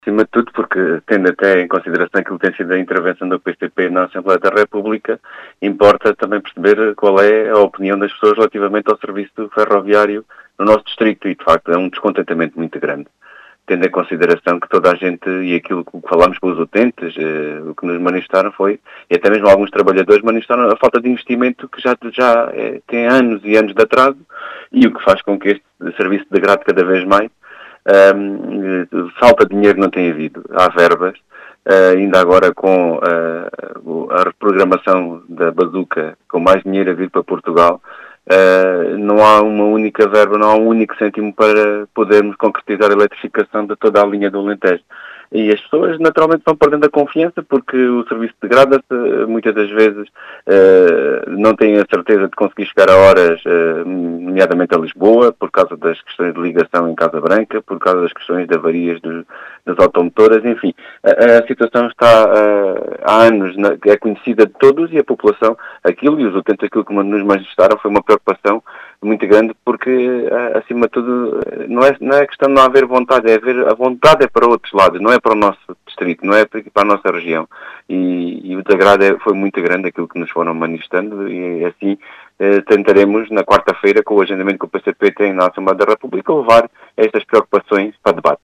Em declarações à Rádio Vidigueira, o deputado do PCP João Dias, diz ter registado um “descontentamento muito grande” com o serviço ferroviário em Beja.